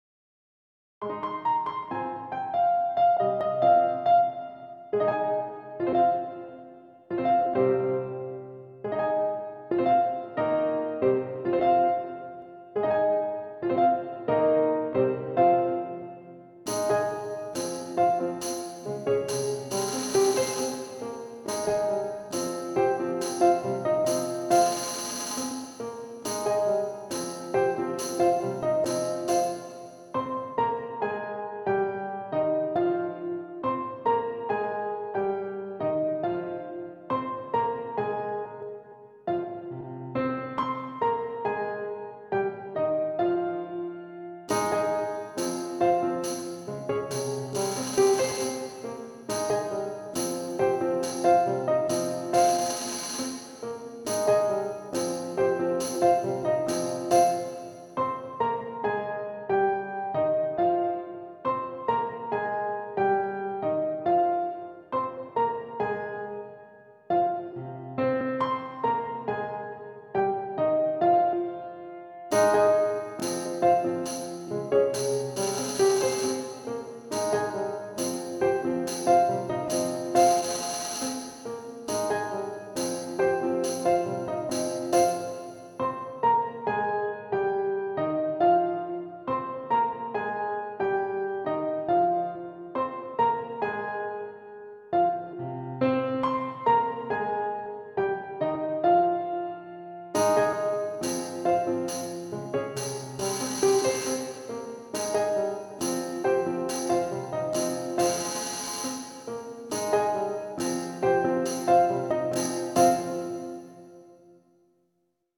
SSA (in English) – Accompaniment Track